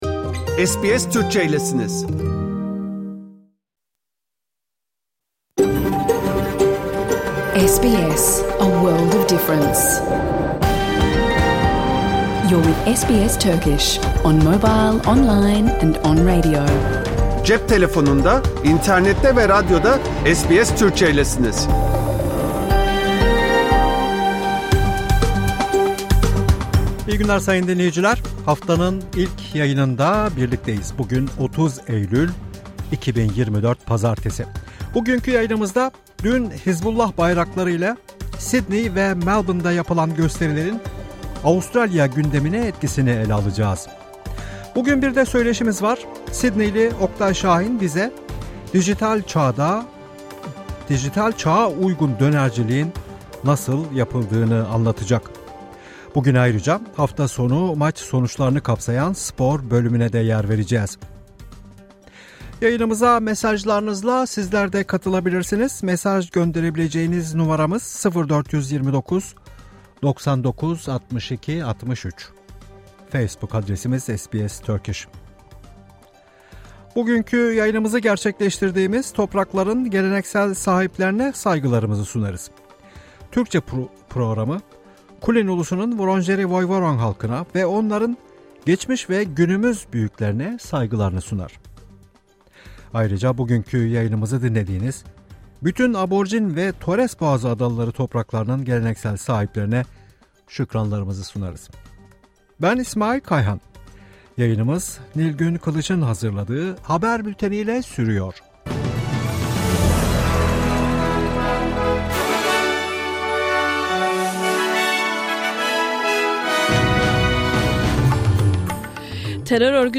Hafta içi Salı hariç hergün her saat 14:00 ile 15:00 arasında yayınlanan SBS Türkçe radyo programını artık reklamsız, müziksiz ve kesintisiz bir şekilde dinleyebilirsiniz.